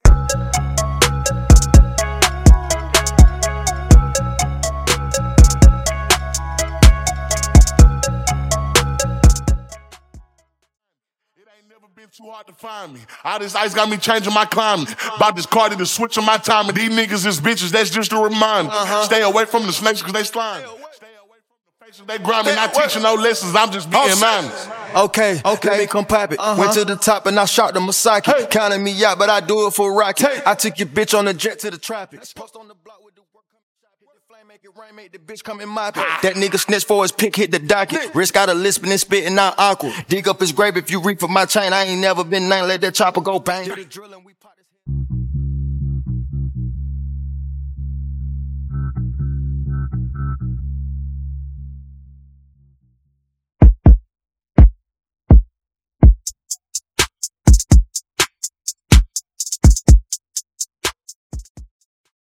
Adlibs
Bass & Subwoofer Stem
Instrumental
Percussion & Drums Stem